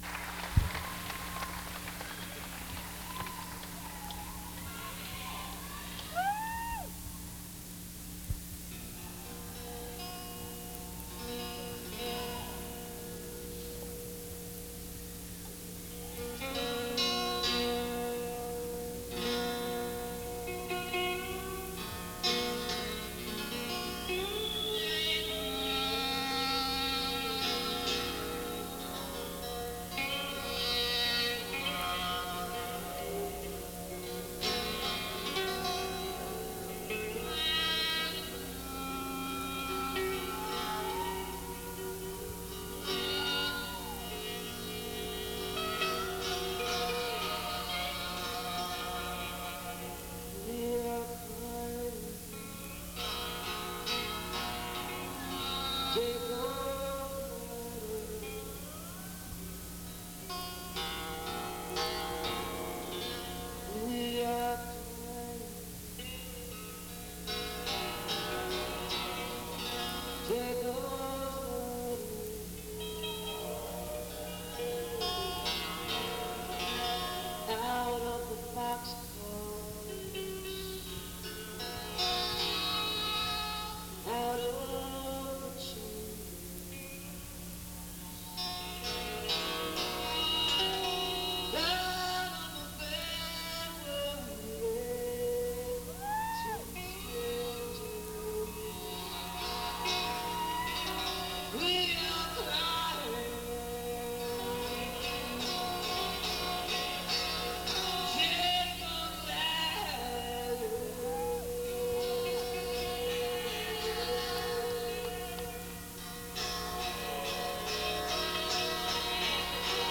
the patriot center - washington, d.c.